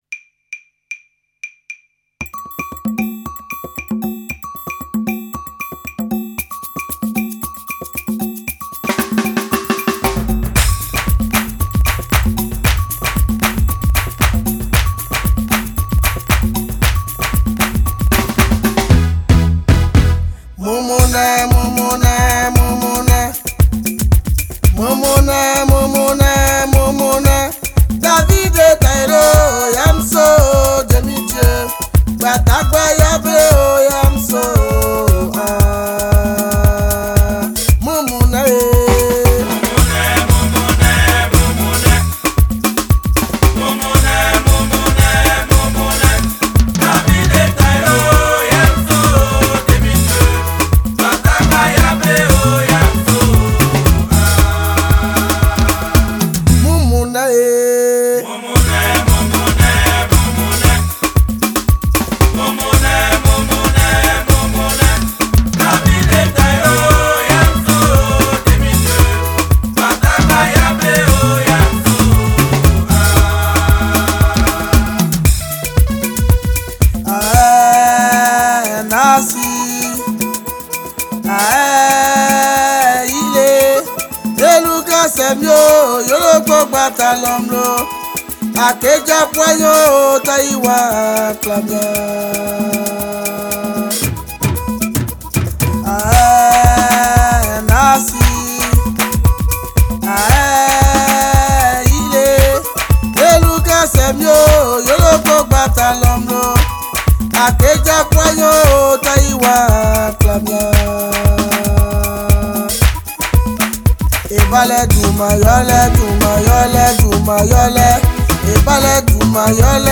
Genre: African